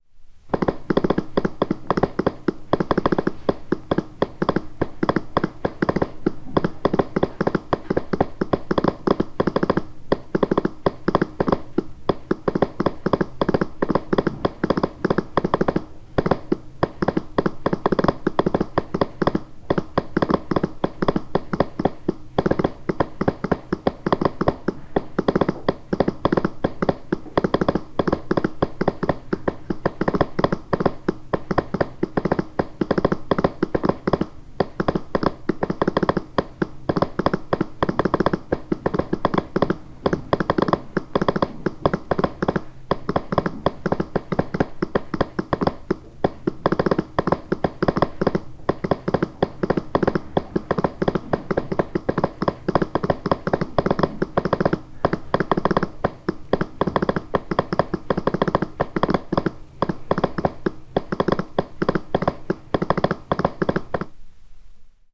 • morse telegraph machine key - morse code.wav
morse_telegraph_machine_key_-_morse_code_UZ5.wav